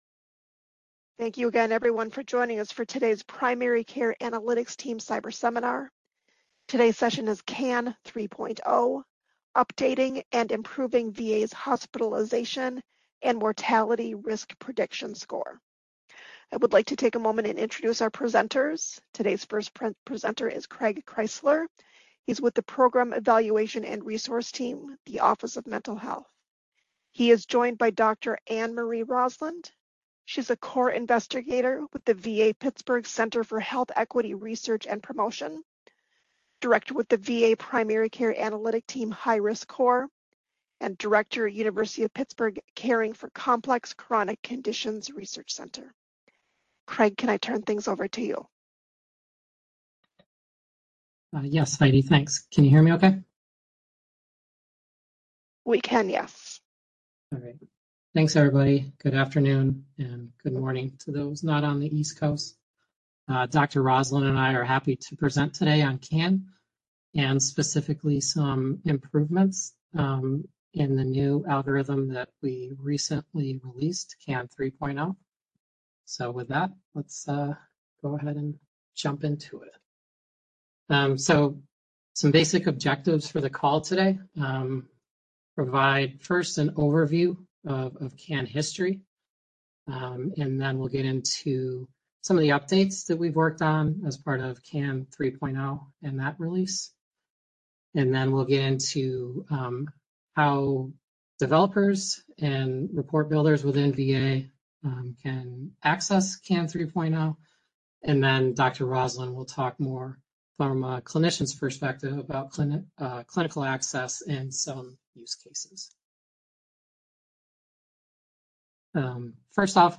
MS Seminar date